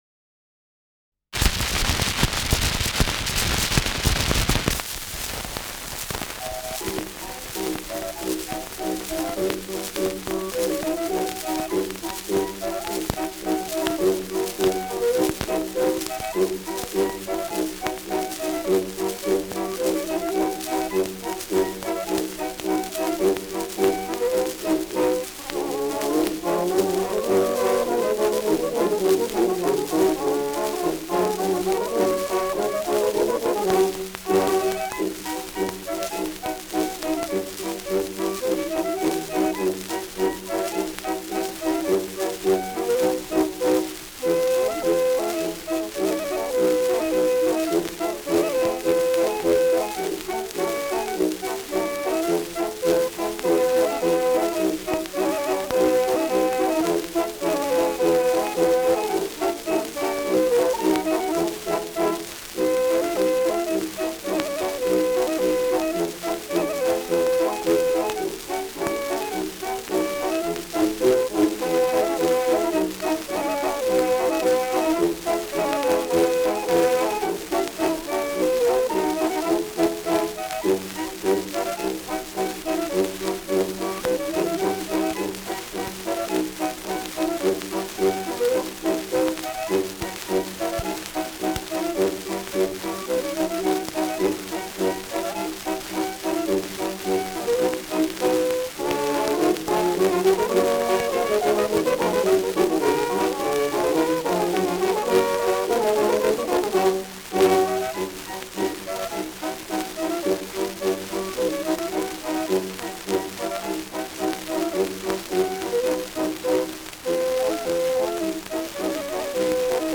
Schellackplatte
ausgeprägtes Rauschen
Monachia Orchester (Interpretation)